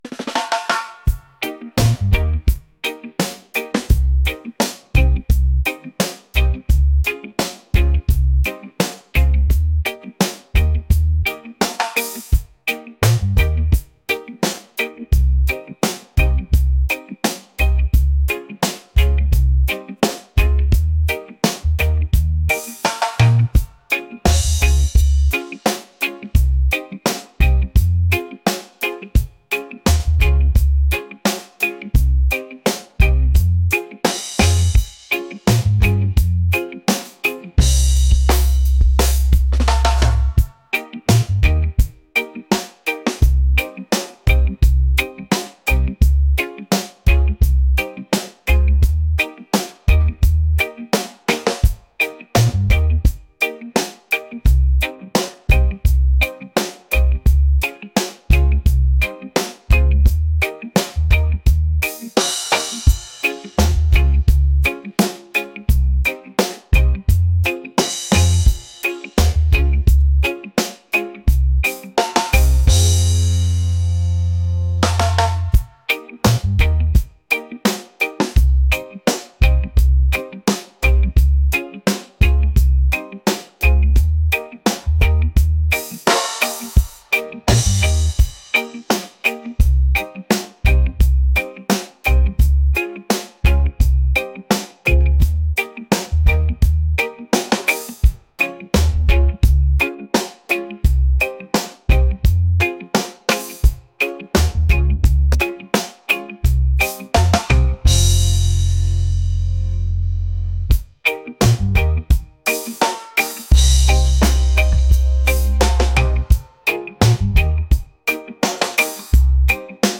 reggae | soulful